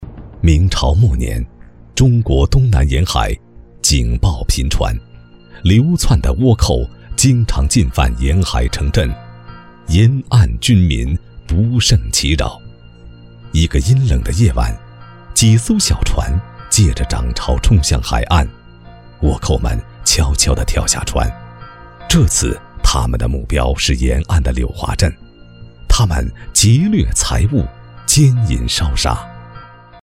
大气浑厚 自然类
娓娓道来、韵味的记录片解说